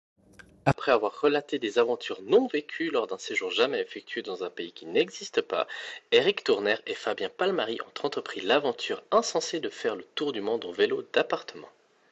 En ce qui concerne le microphone, voici un exemple de son rendu. Notez que dans un endroit bondé de monde, la captation est difficile, mais ce n’est évidemment pas un produit destiné à cette utilisation.